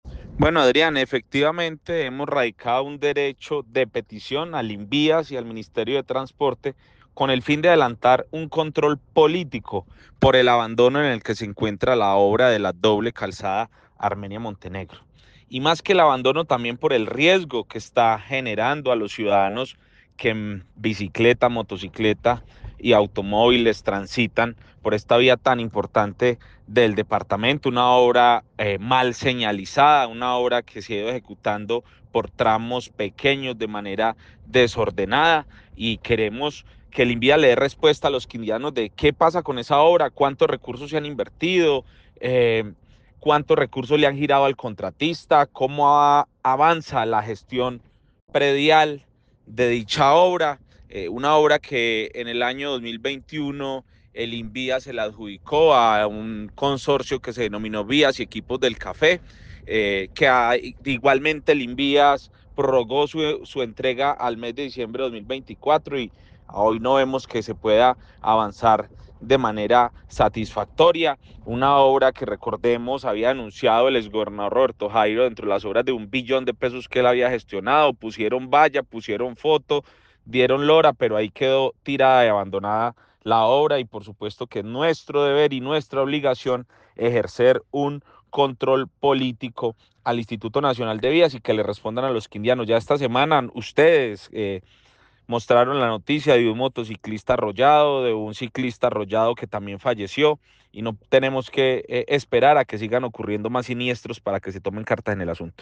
Jhon Edgar Pérez, Representante a la Cámara del Quindío
En Caracol Radio Armenia hablamos con el Representante a la Cámara de Cambio Radical por el Quindío, Jhon Edgar Pérez que habló precisamente de esta obra que tiene retrasos, problemas, prorrogas y demás.